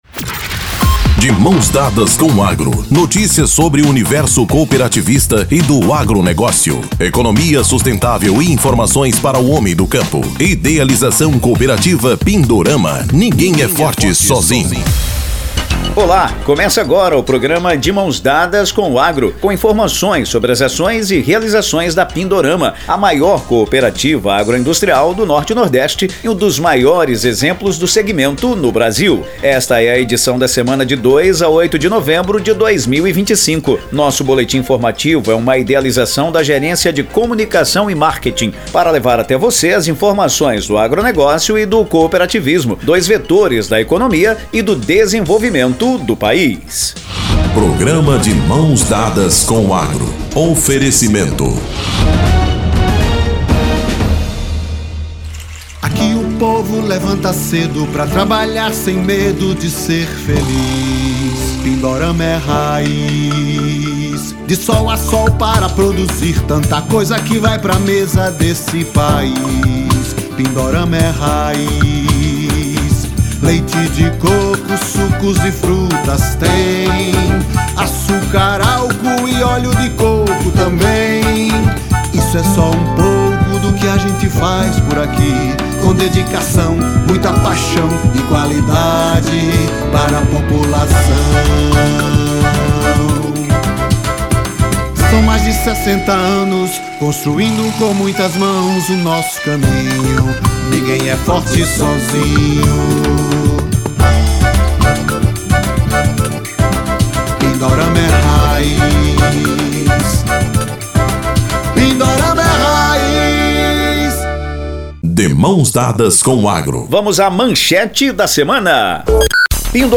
Idealizado pela Gerência de Comunicação e Marketing da Cooperativa Pindorama, o boletim semanal traz as principais notícias sobre o universo do agronegócio e do cooperativismo.